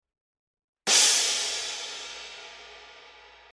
Loud Hit